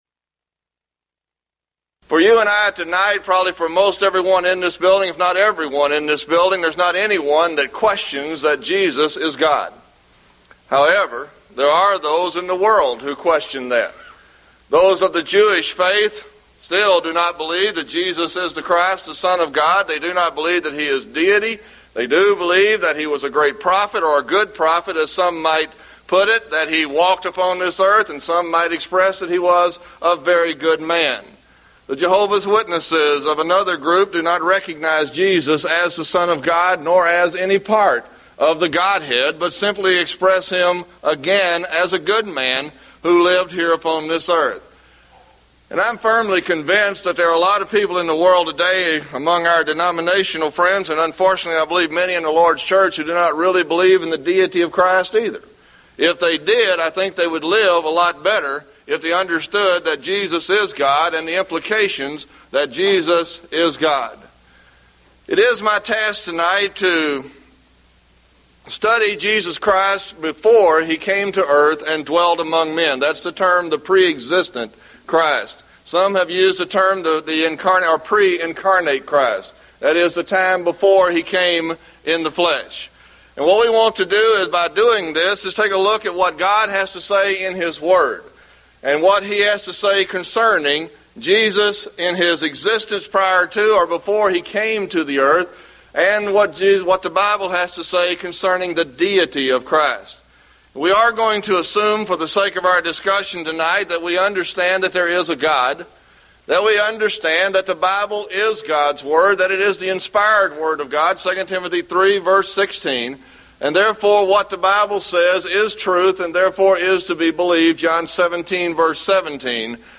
Event: 1998 Mid-West Lectures
lecture